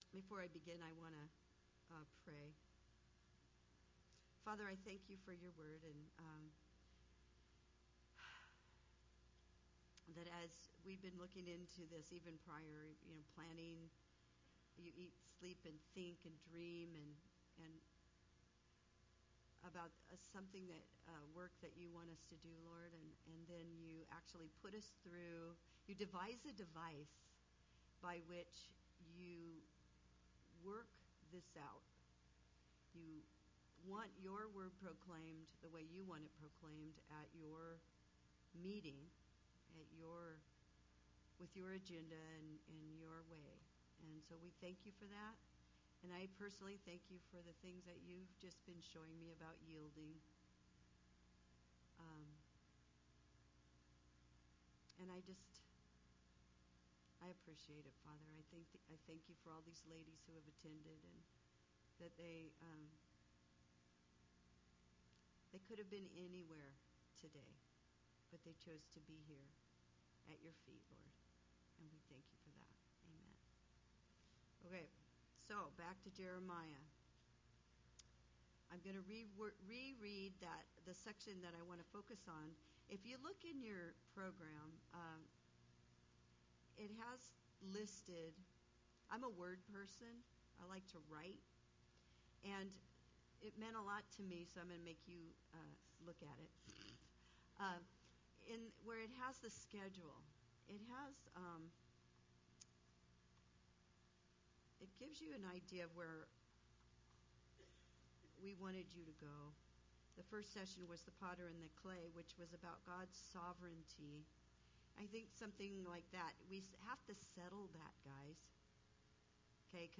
2015 Women's Conference